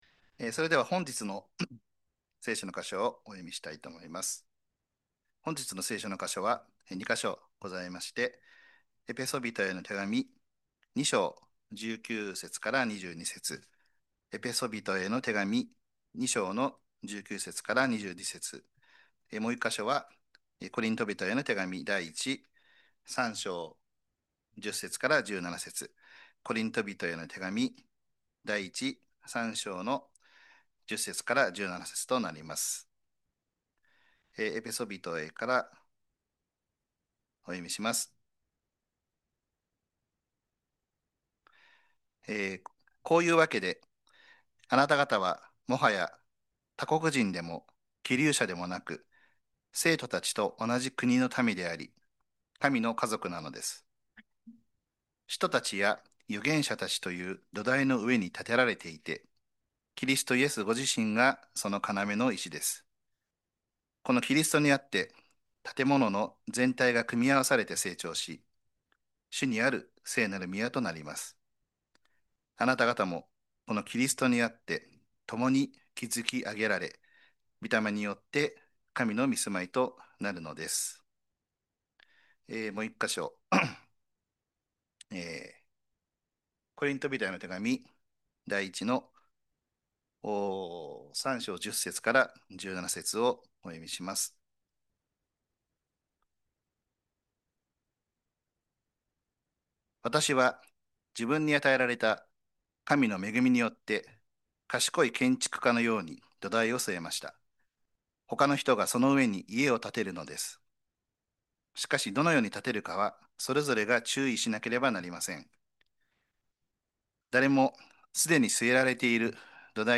2026年1月4日礼拝 説教 「めぐみ教会の設計図：教会の『成長』とは？」